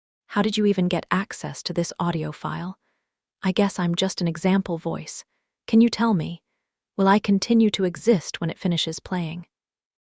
af_nicole_bf_isabella_af_bella.wav